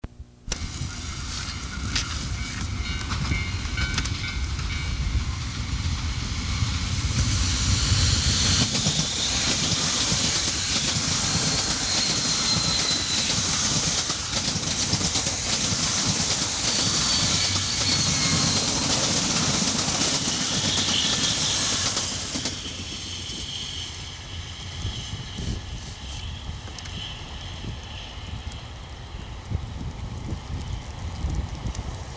極限の気動車１８１系----衝撃のターボサウンド
通　過　音
伯耆大山駅周辺各種列車通過音